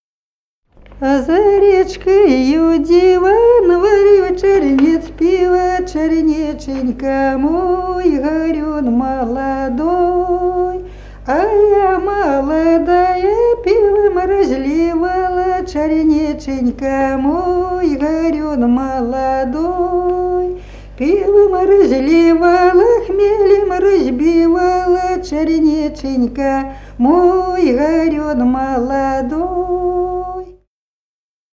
Народные песни Смоленской области
«А за речкою диво» (игровая).